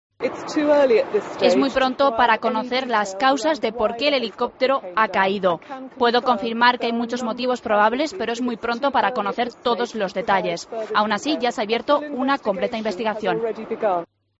AUDIO: Tragedia en Glasgow: Escuche el testimonio de un policía